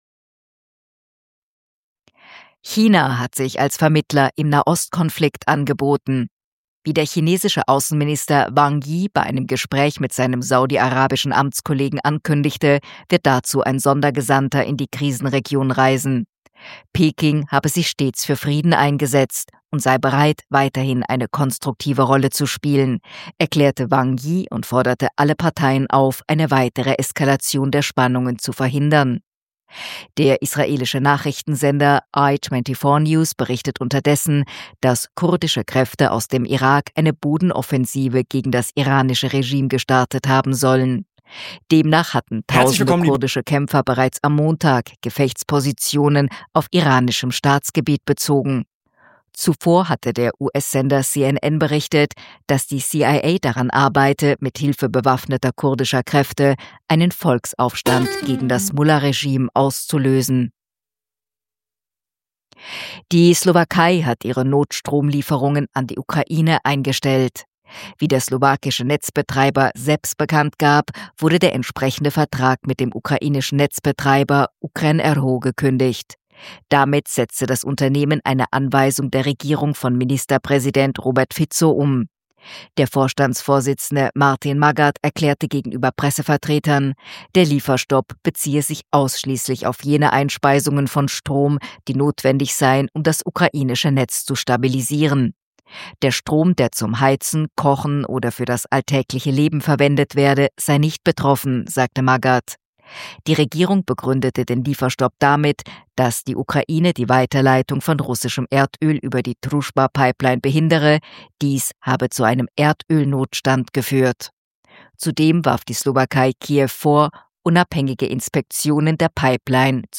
Kontrafunk aktuell 5.3.2026 – Nachrichten vom 5.3.2026